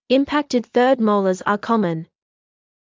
ｲﾝﾊﾟｸﾃｨｯﾄﾞ ｻｰﾄﾞ ﾓﾗｰｽﾞ ｱｰ ｺﾓﾝ